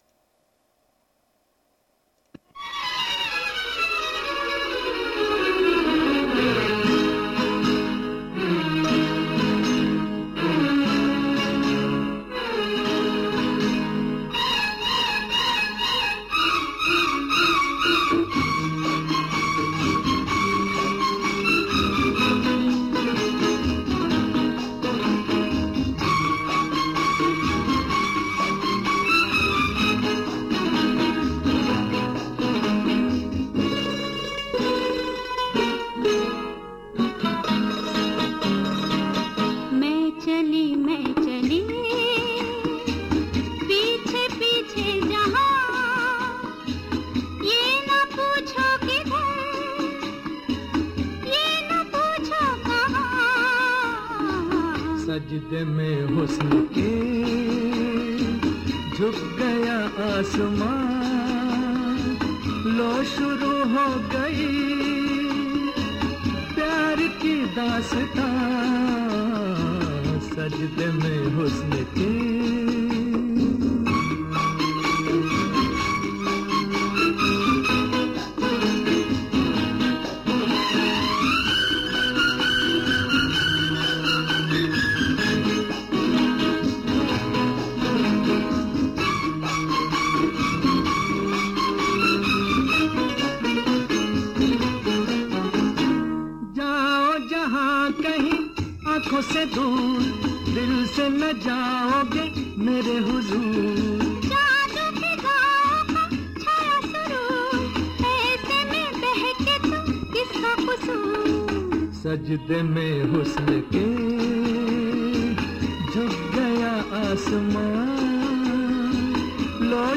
Bollywood Classic songs